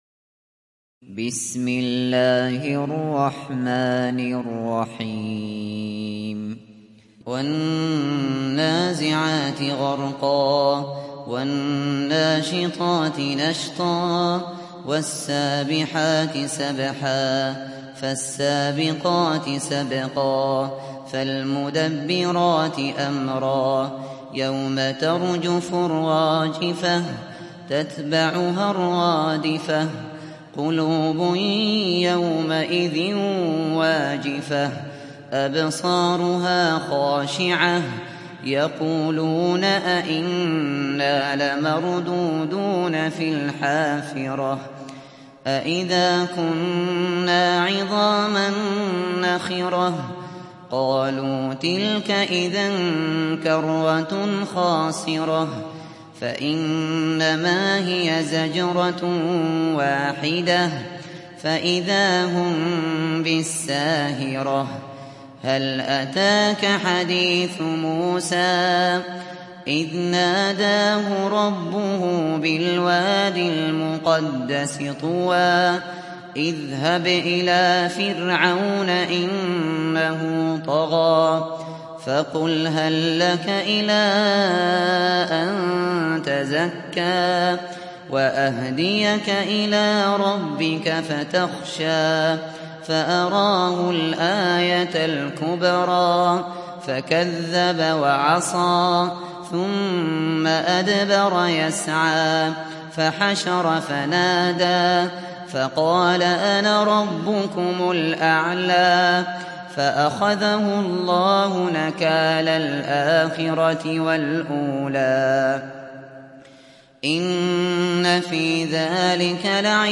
تحميل سورة النازعات mp3 بصوت أبو بكر الشاطري برواية حفص عن عاصم, تحميل استماع القرآن الكريم على الجوال mp3 كاملا بروابط مباشرة وسريعة